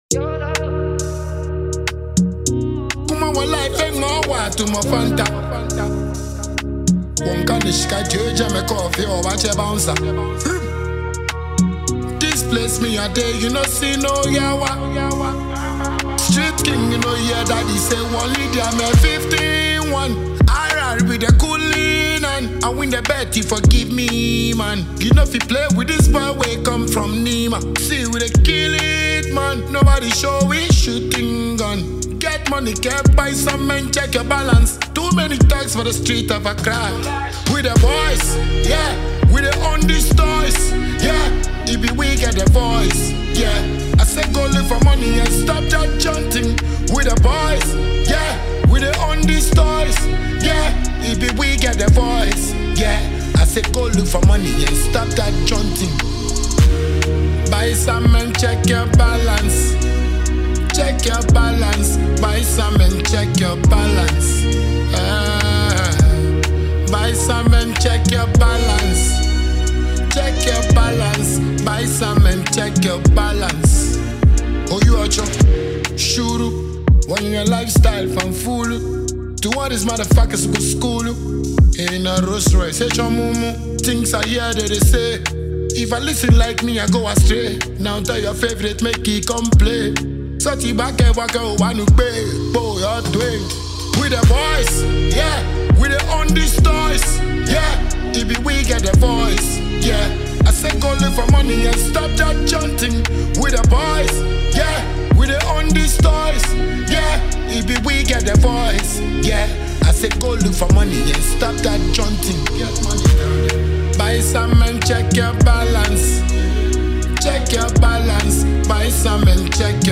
Ghanaian dancehall artiste